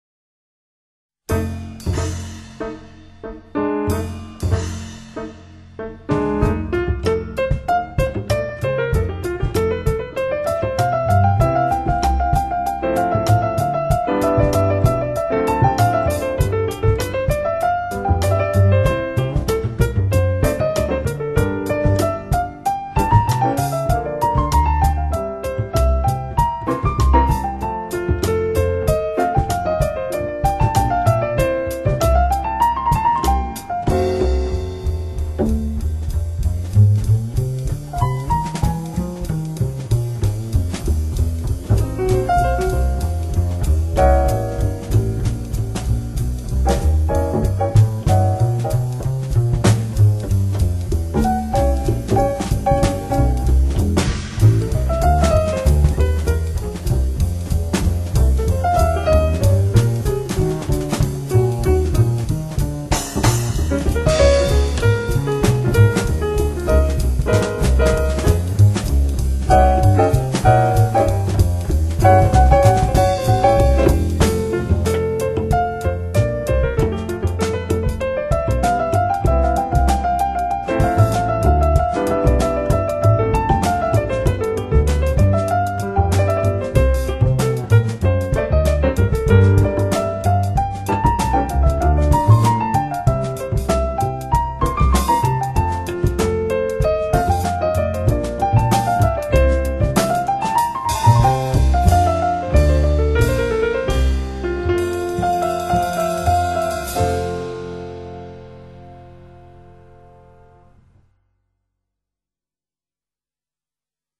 唱片也是这样，钢琴的声音除了通透之外，泛音非常丰富；鼓声的实体感非常强
烈，贝司的弹性极其饱满。
而且立体感超强。